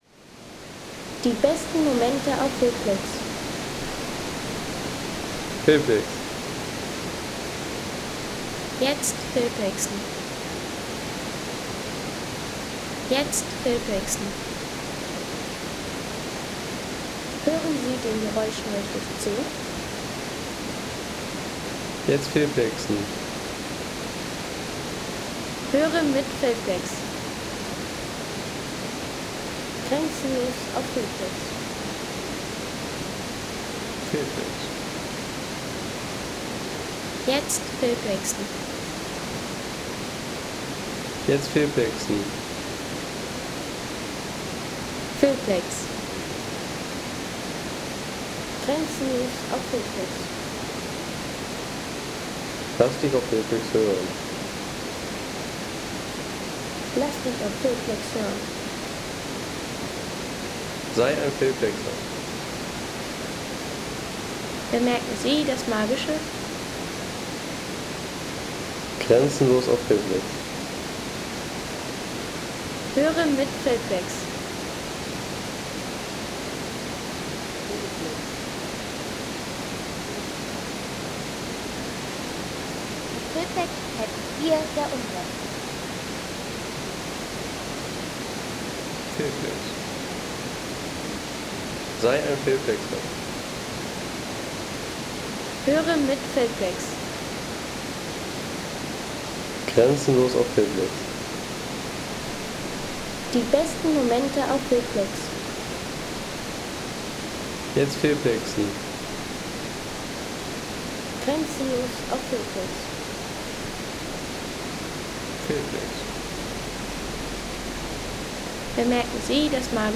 Wasserfall an Tauernbachbrücke
Tauernbachfall: Klang der Natur von der Tauernbachbrücke.